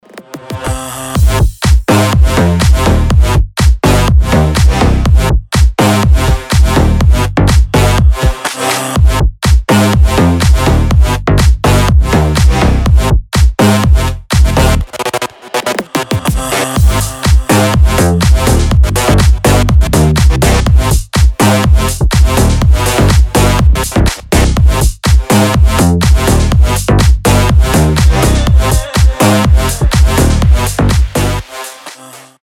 • Качество: 320, Stereo
громкие
жесткие
EDM
мощные басы
Brazilian bass
взрывные
По-царски роскошный звонок с крутыми басами